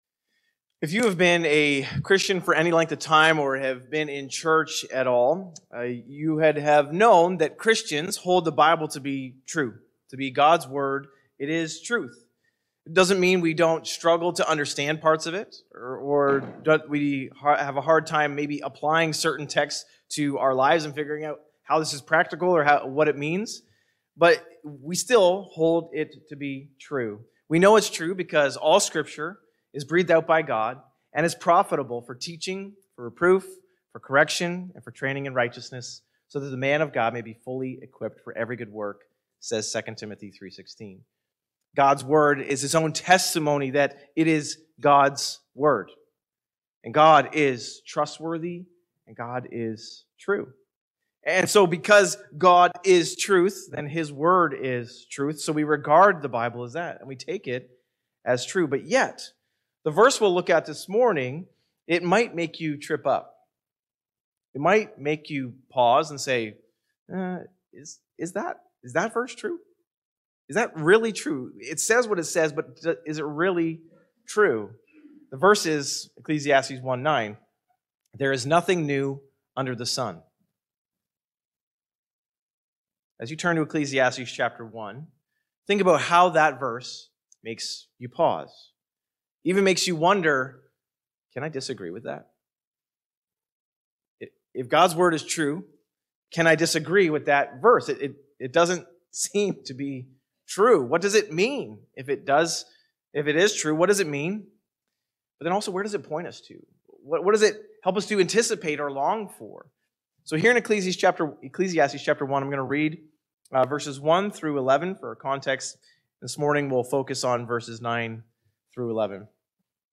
Sermons | West Lorne Baptist Church